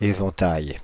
Ääntäminen
Synonyymit gamme Ääntäminen Paris: IPA: [e.vɑ̃.taj] France (Île-de-France): IPA: /e.vɑ̃.taj/ Haettu sana löytyi näillä lähdekielillä: ranska Käännös Konteksti Ääninäyte Substantiivit 1. fan puutarhanhoito 2. range US 3. array US Suku: m .